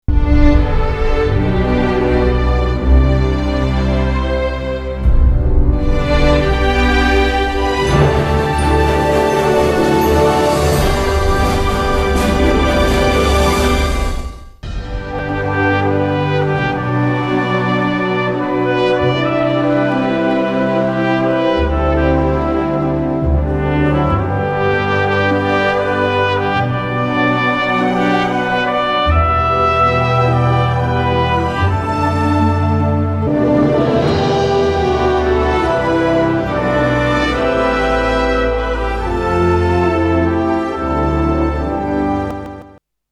片尾曲是用小号演奏出来的乐曲